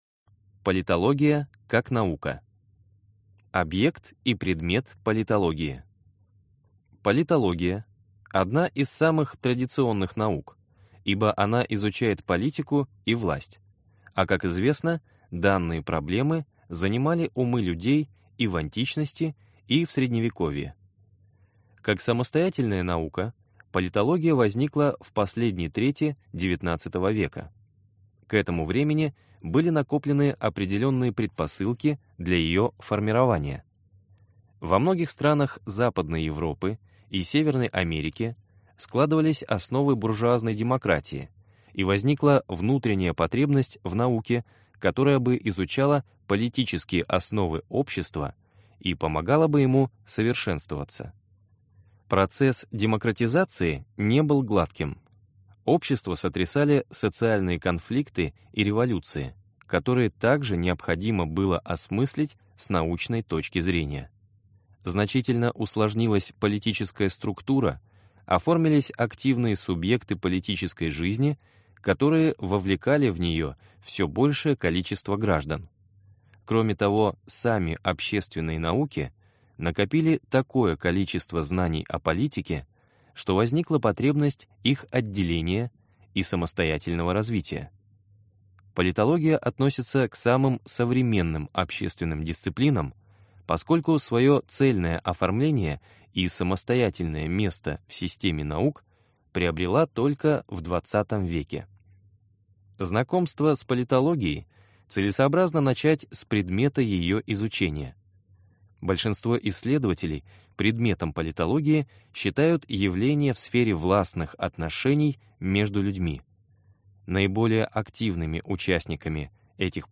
Аудиокнига Политология. Курс лекций | Библиотека аудиокниг